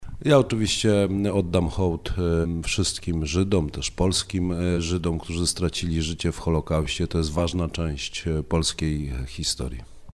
Karol Nawrocki – kandydat na urząd prezydenta popierany przez PiS był gościem Radia Rodzina.